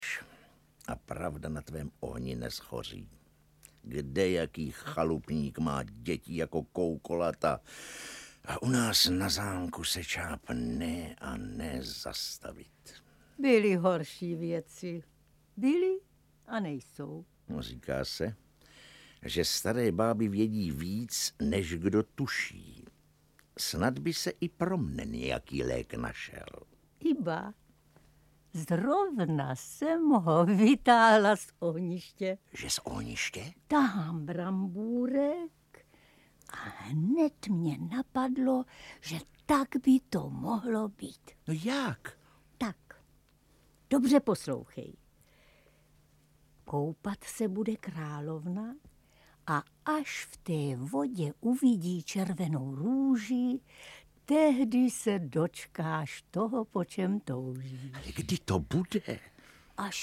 Audiobook
Read: Jaroslav Satoranský